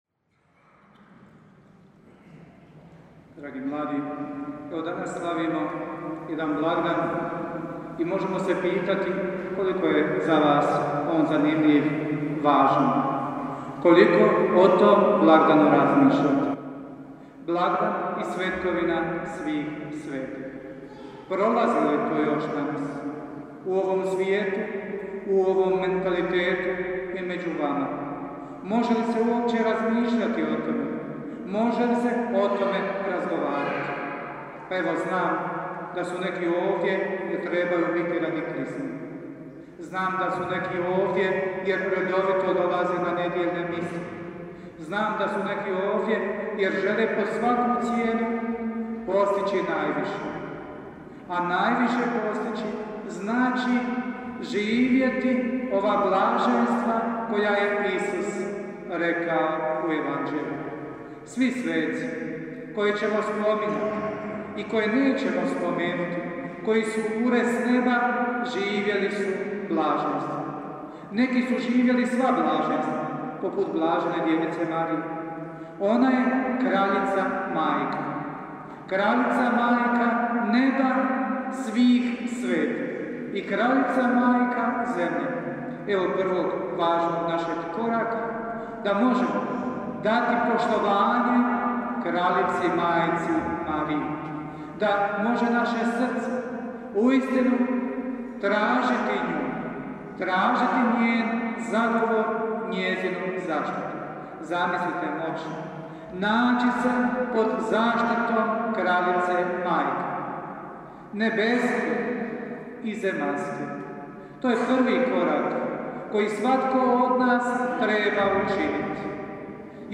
PROPOVJED:
sv. MISA @ Župna Crkva
SSZC2015_a_PROPOVJED.mp3